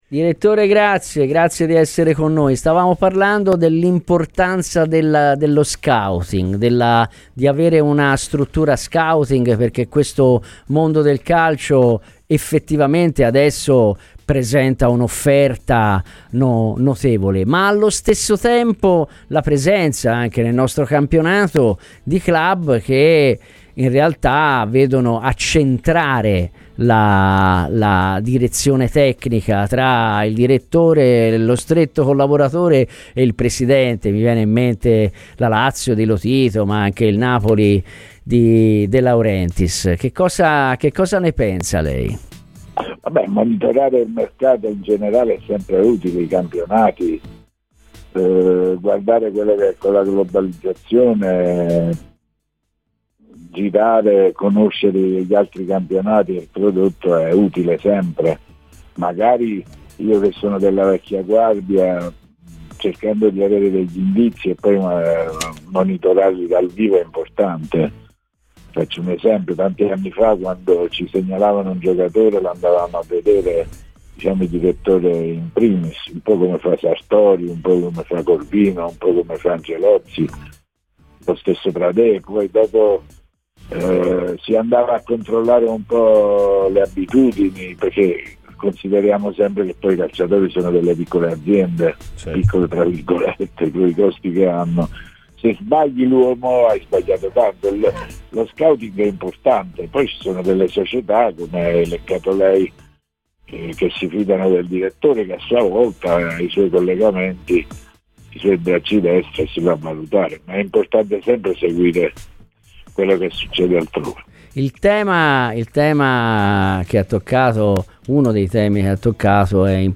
trasmissione di approfondimento